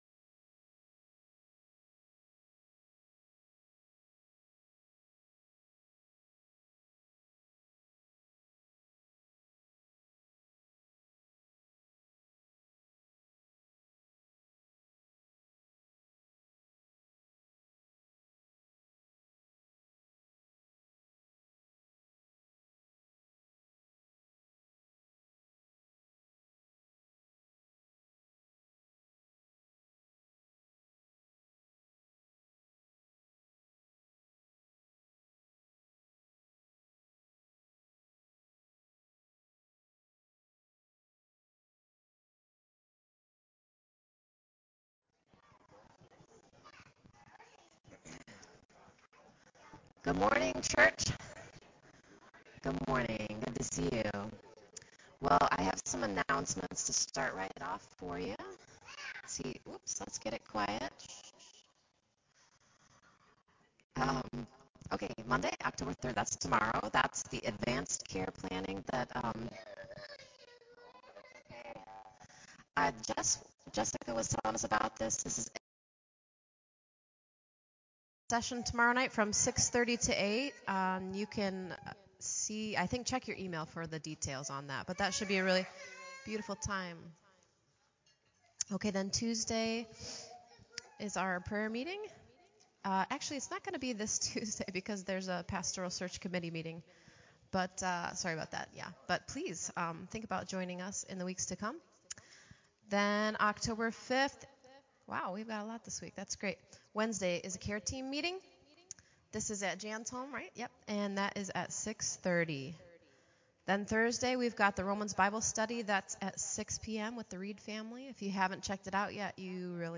October 2nd 2022 worship
Praise Worship
Prayer Requests